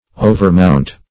Search Result for " overmount" : The Collaborative International Dictionary of English v.0.48: Overmount \O`ver*mount"\, v. t. [Cf. Surmount .] To mount over; to go higher than; to rise above.